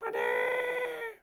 Audio / SFX / Characters / Voices / PigChef / PigChef_14.wav